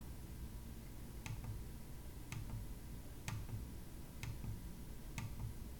左右クリックボタンは静音仕様。
マウス操作時の音を録音しました。
egret-prettie-mouse-dpi-button-sound-3.mp3